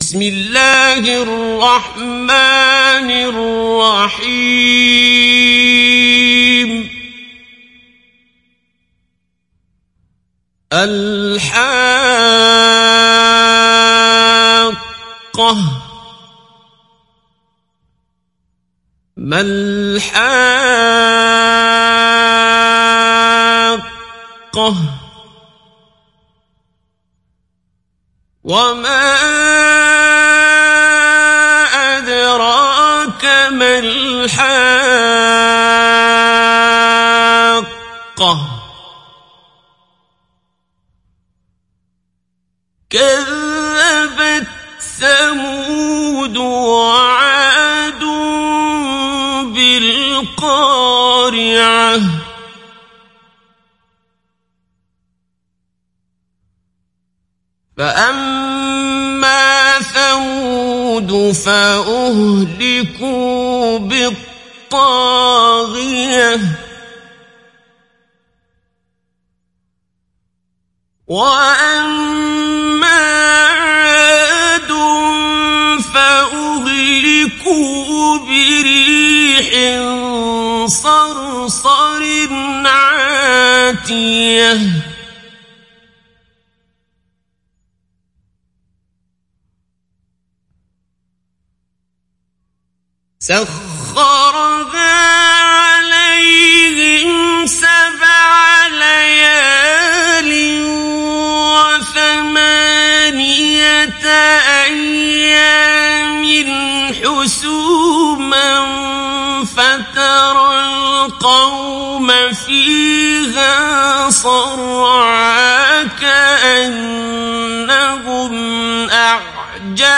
دانلود سوره الحاقه mp3 عبد الباسط عبد الصمد مجود روایت حفص از عاصم, قرآن را دانلود کنید و گوش کن mp3 ، لینک مستقیم کامل
دانلود سوره الحاقه عبد الباسط عبد الصمد مجود